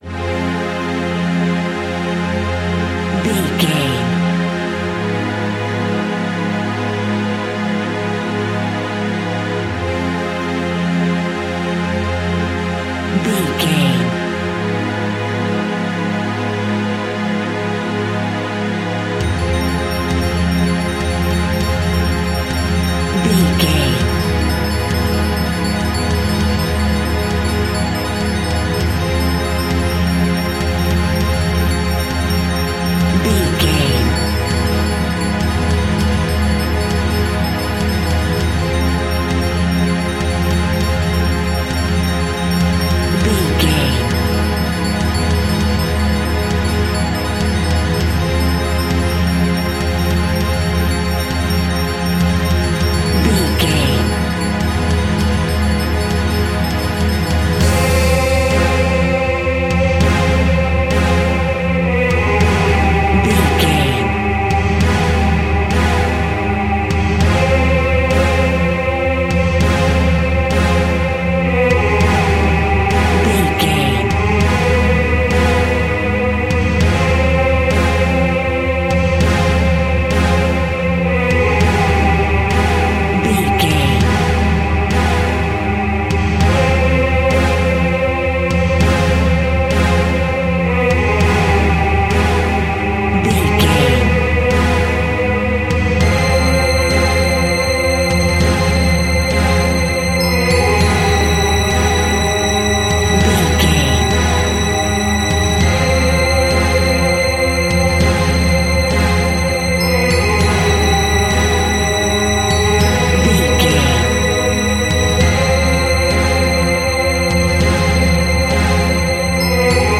In-crescendo
Thriller
Aeolian/Minor
ominous
eerie
strings
percussion
synthesiser
Horror Synths